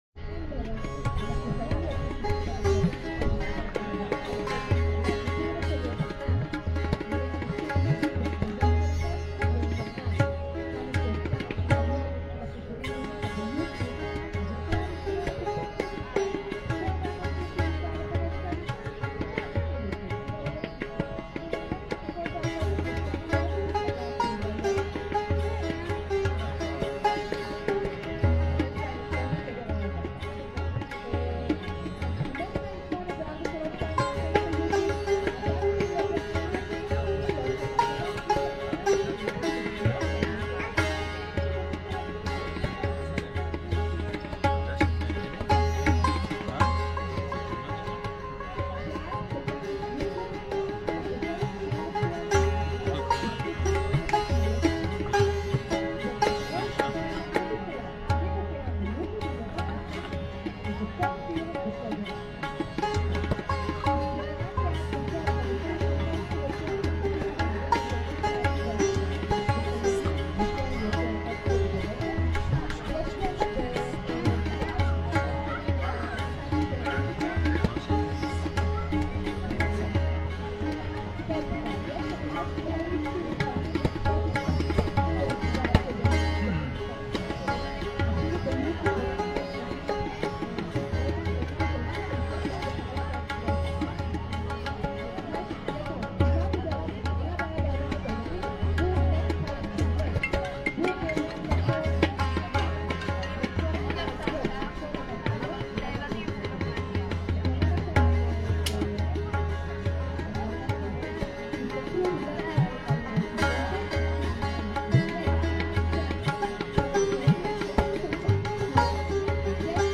מנגנים ראגה בימפאלאסי בחוף לידו